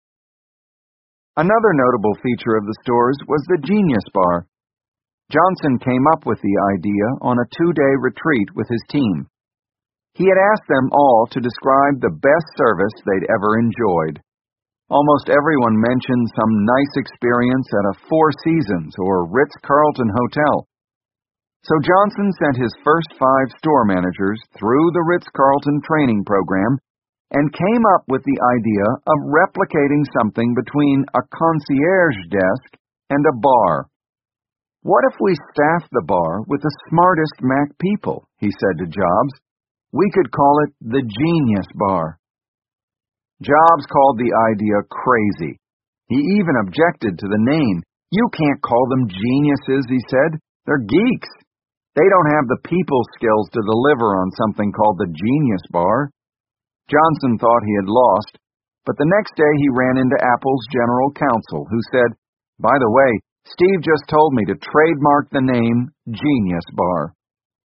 本栏目纯正的英语发音，以及完整的传记内容，详细描述了乔布斯的一生，是学习英语的必备材料。